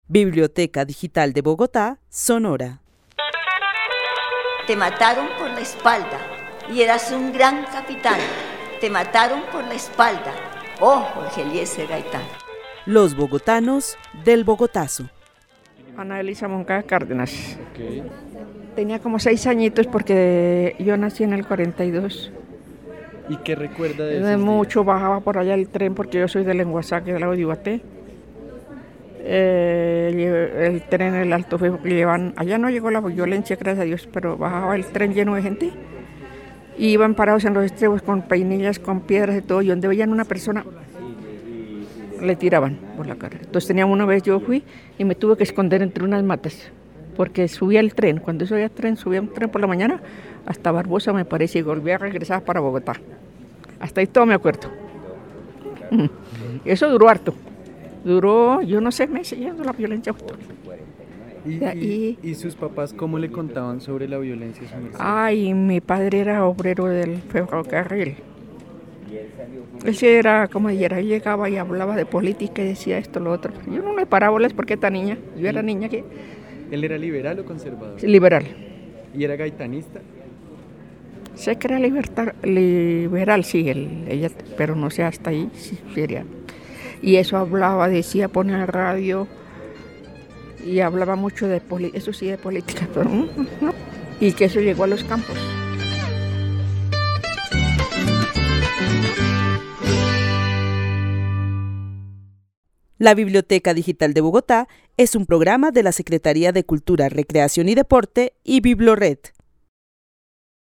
Narración oral de los hechos sucedidos en Bogotá el 9 de abril de 1948. Cuenta cómo la violencia afectó su vida en Lenguazaque, Cundinamarca. El testimonio fue grabado en el marco de la actividad "Los bogotanos del Bogotazo" con el club de adultos mayores de la Biblioteca El Tunal.